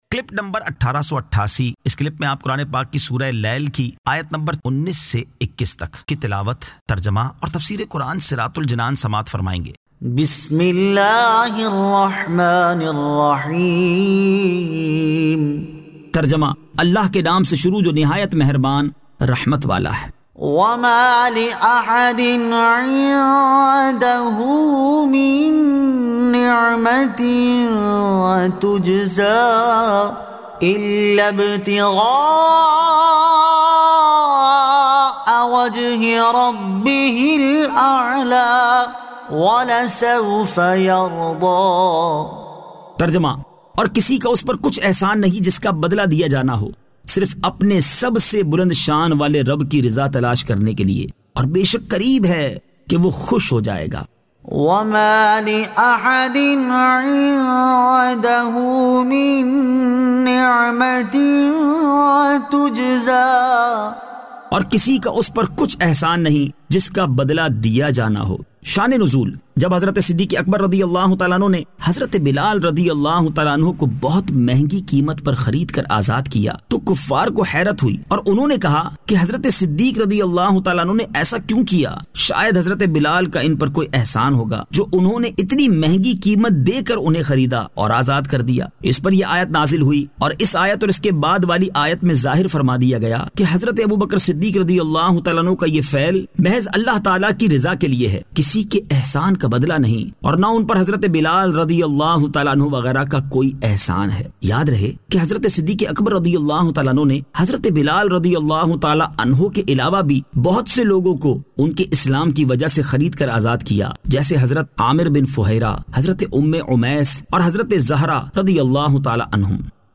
Surah Al-Lail 19 To 21 Tilawat , Tarjama , Tafseer